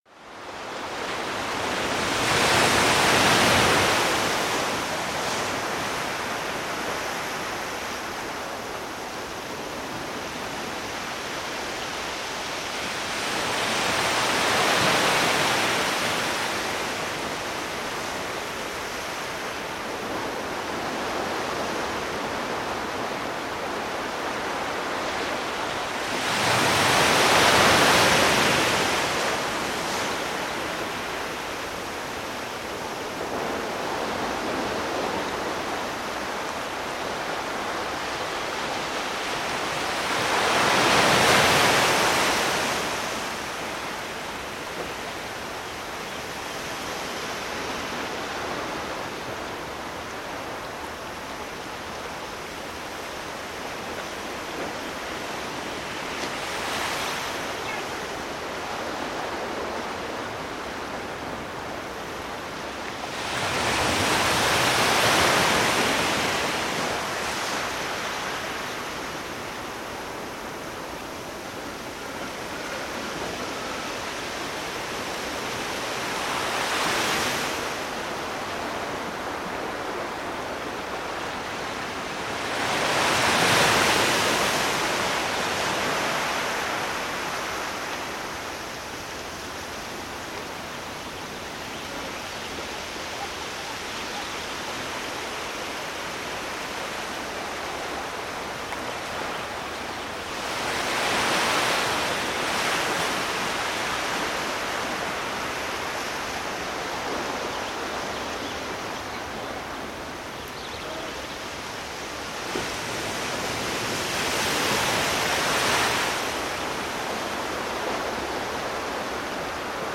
Морской_прибой
Morskoq_priboq.mp3